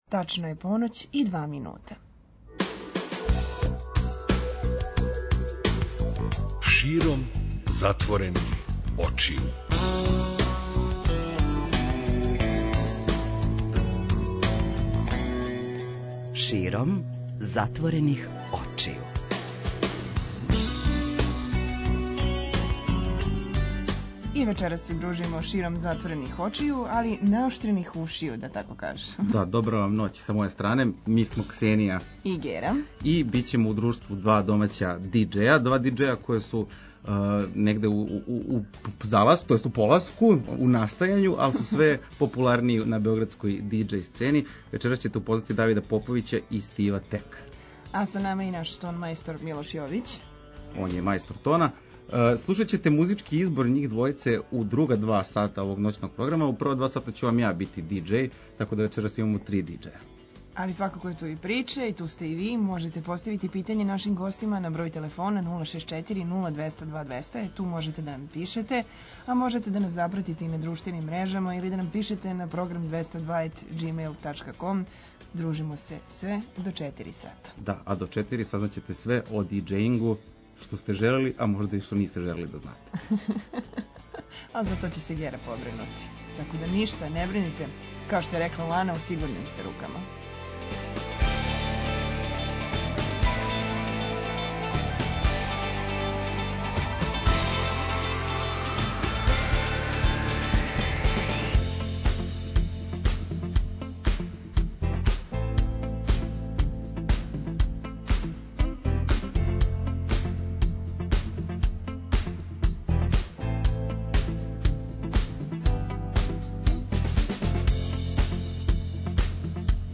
преузми : 54.17 MB Широм затворених очију Autor: Београд 202 Ноћни програм Београда 202 [ детаљније ] Све епизоде серијала Београд 202 Тешке боје Устанак Устанак Устанак Брза трака